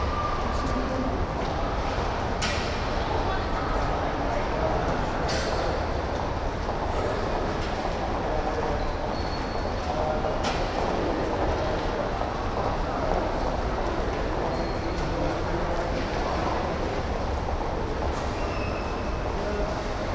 hall_large_mono.wav